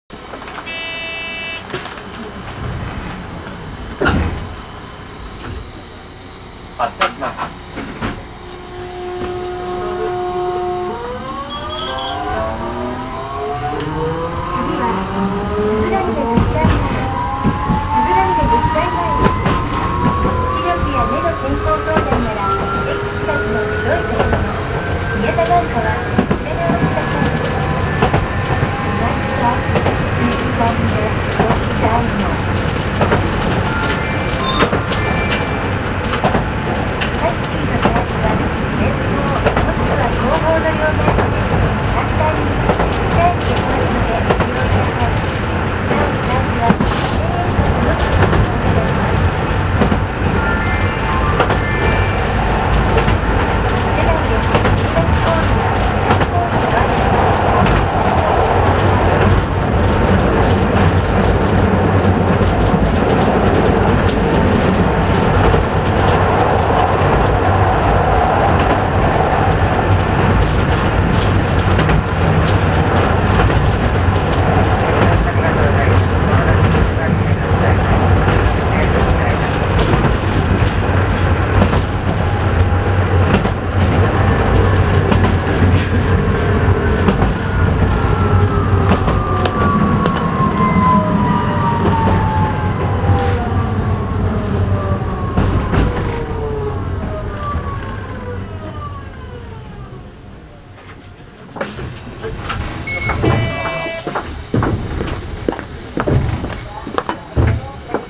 〜車両の音〜
・3950形走行音
【宮島線】井口〜鈴峯女子大前（1分48秒：588KB）
東洋電機製のVVVFインバータを採用していますが、よく聞く音ではなく、もっと初期の物を使用しています。